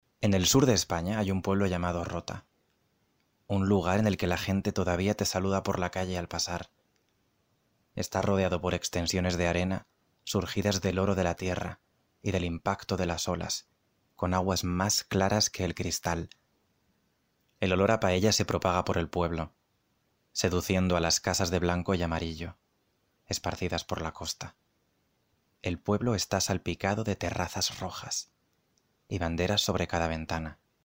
Voix Espagnole
Voix off
36 - 50 ans - Baryton-basse